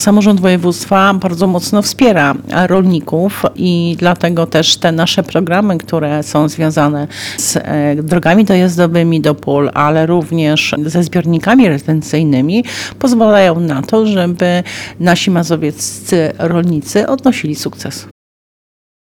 Mówi Janinia Ewa Orzełowska, członkini zarządu województwa mazowieckiego.